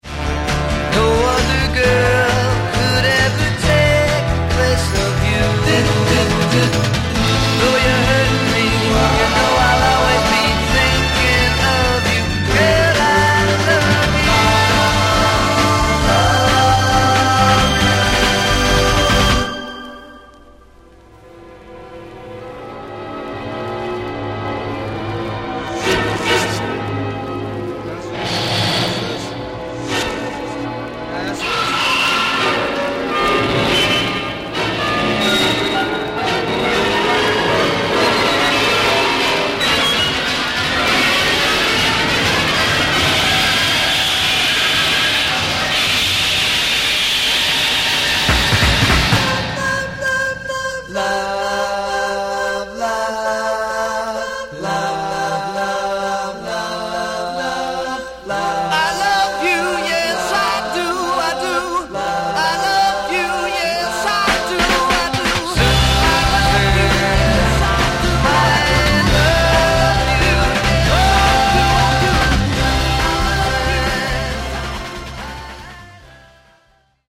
Genre: Sunshine Pop